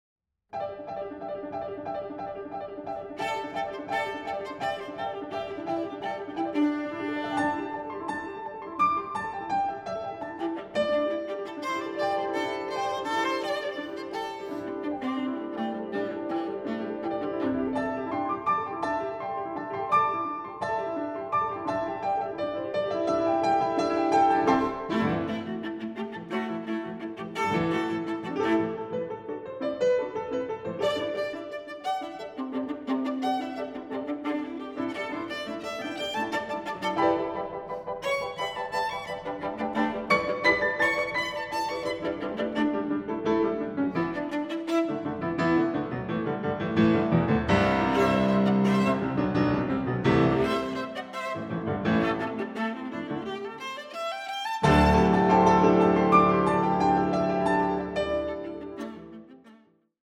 Viola
Klavier